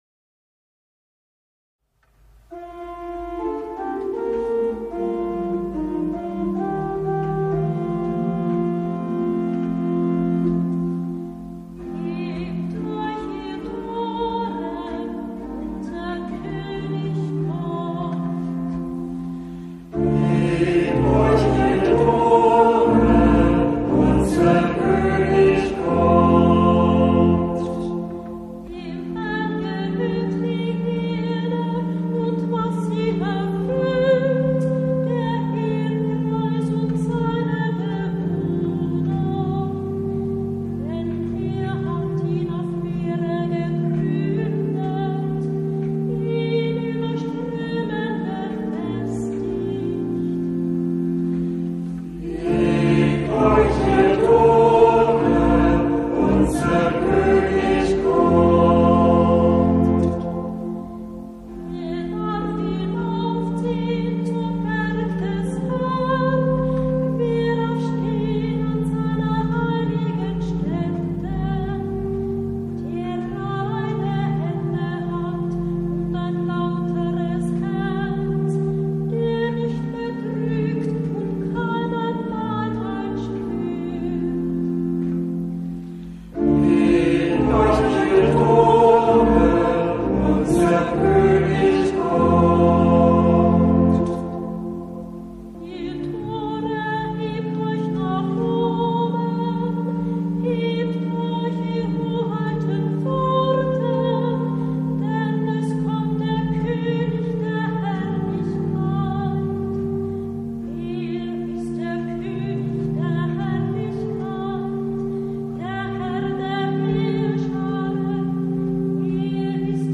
Hörbeispiele aus verschiedenen Kantorenbüchern
Psalmen aus dem Gurker Psalter für Kantor mit Orgel- oder Gitarrenbegleitung finden Sie hier, geordnet nach den Lesejahren ABC und den Festen bzw.